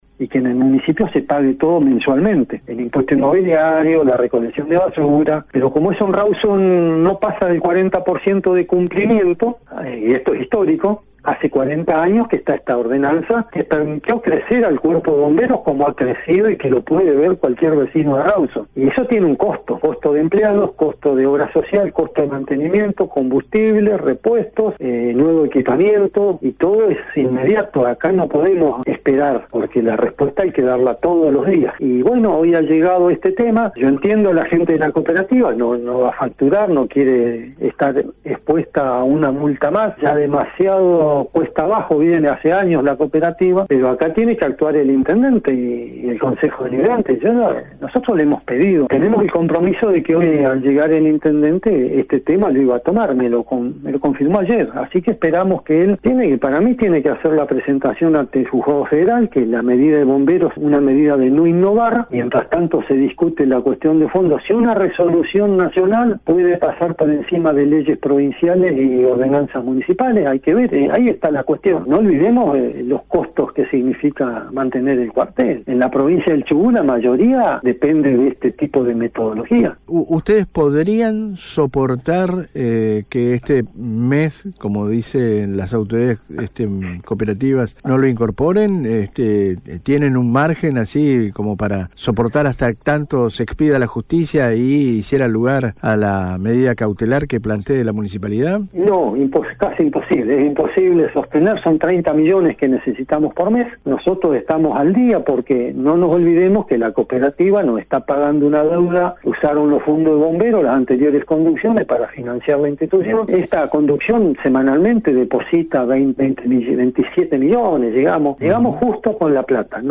explicó en el programa Radio Portátil